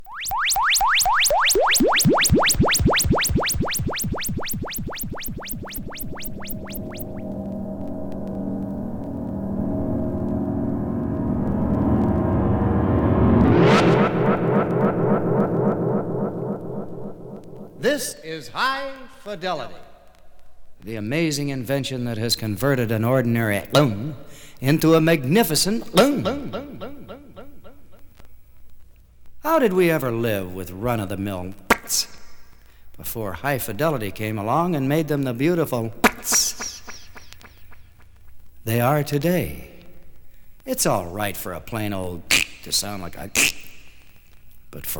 Jazz, Comedy　USA　12inchレコード　33rpm　Mono